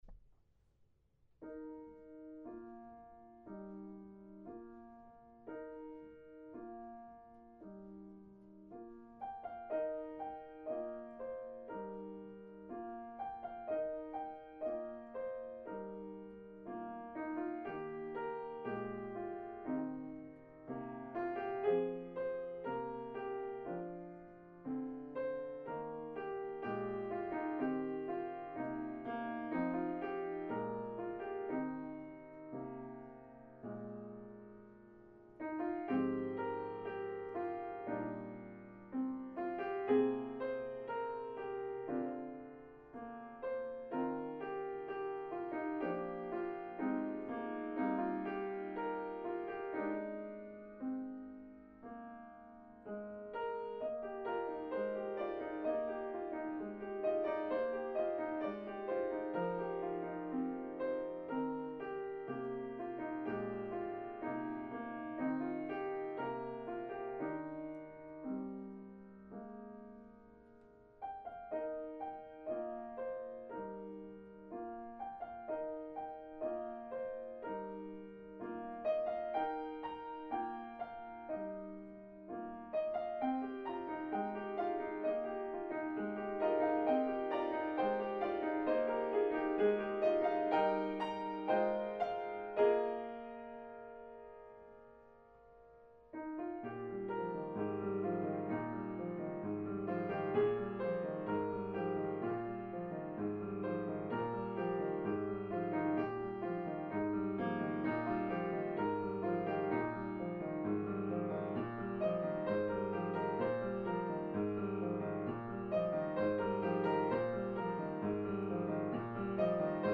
I was sitting in church last week when this hymn was sung, and I thought, I kind of like this one.  I don’t think I had heard it in quite a while, but the tune is very familiar (based on a traditional English melody) and feels like a pleasant walk in nature to me.